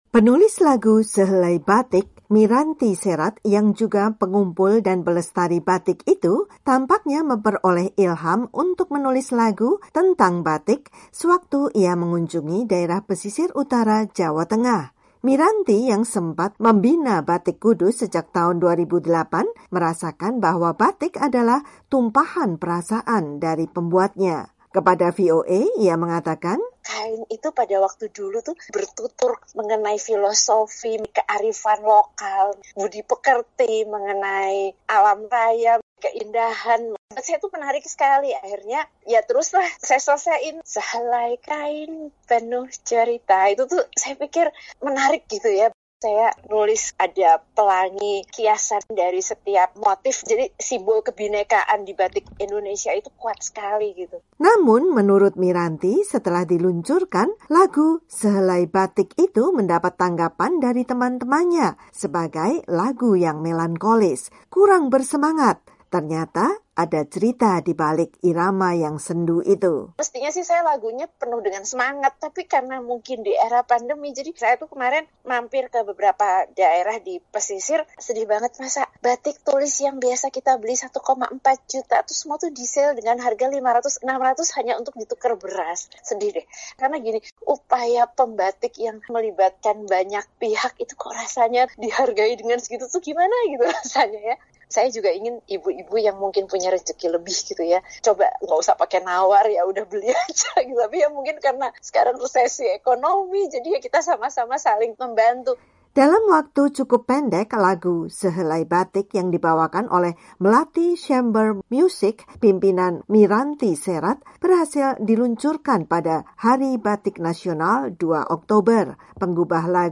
Lembut dan sendu